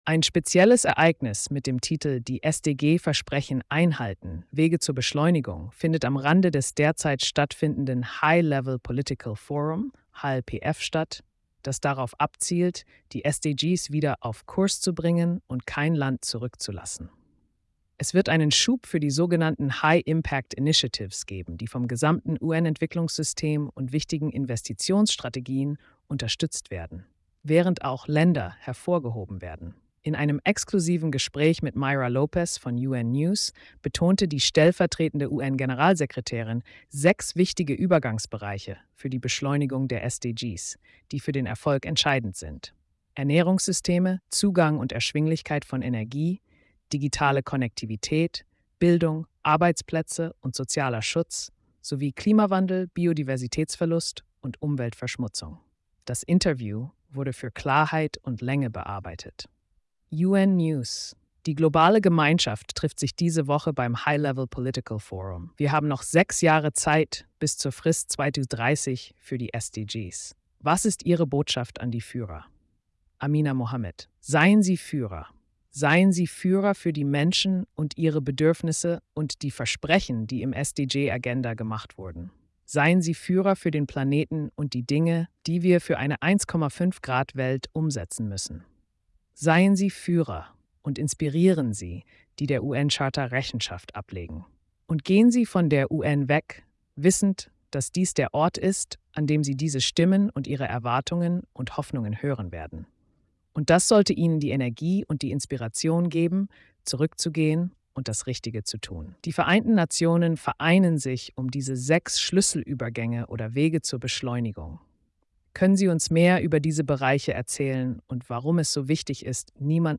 Das Interview wurde für Klarheit und‌ Länge bearbeitet.